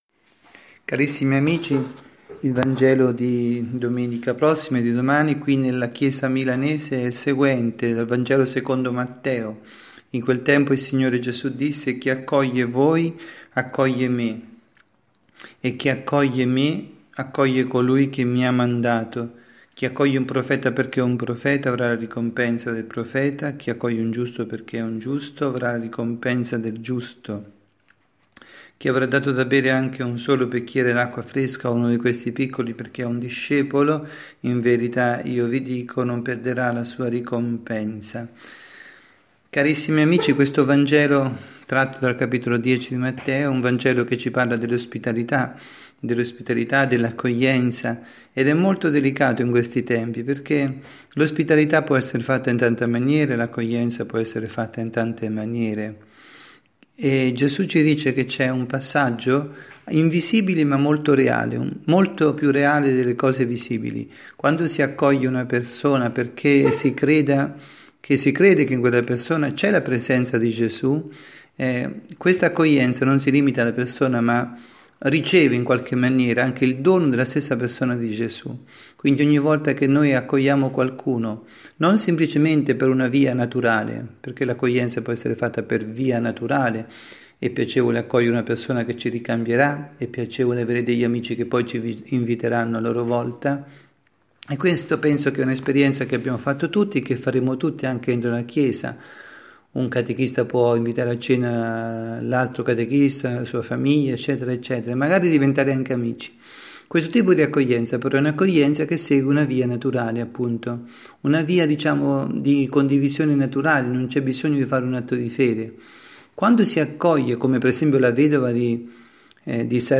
“Povertà” Catechesi